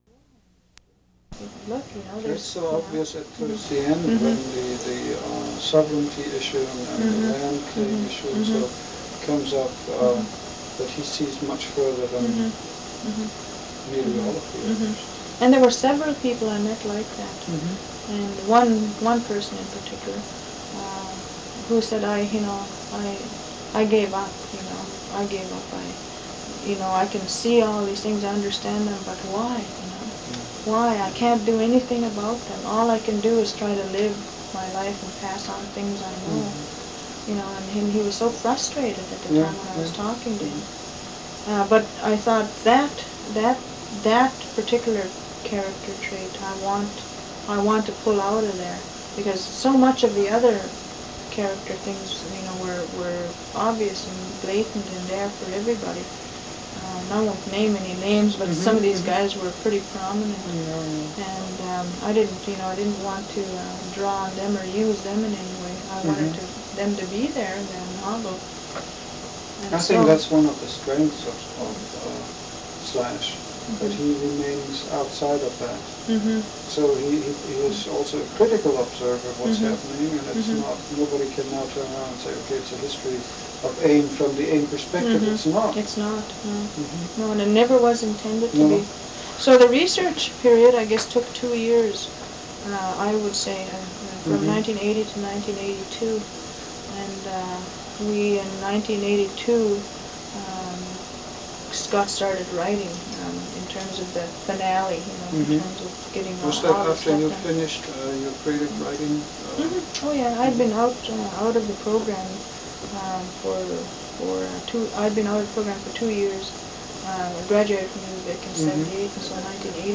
Armstrong, Jeannette Armstrong, Jeannette (Speaker)
Sound, Audio Track, Sound Recording, Non-Fiction, Aboriginal, Indigenous, First Nations